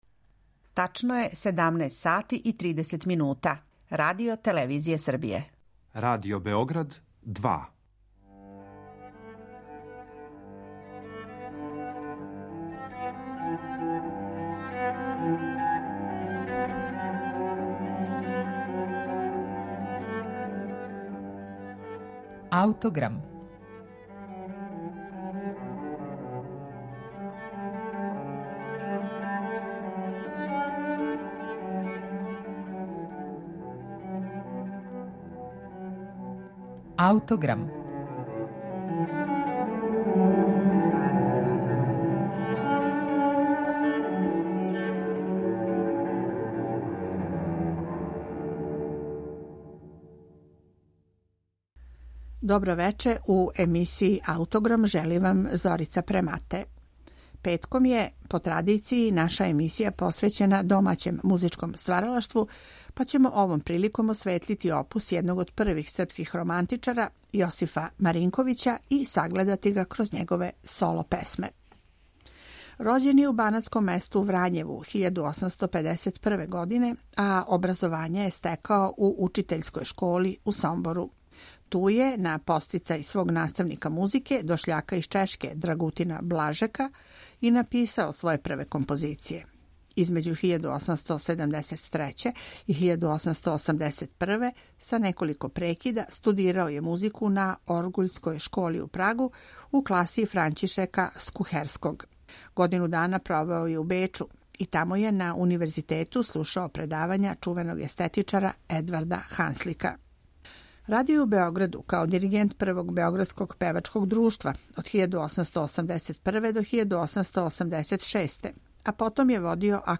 Овога пута слушаћете вокалну лирику нашег великог романтичара Јосифа Маринковића.
соло-песме
бас
мецосопран
клавирску сарадњу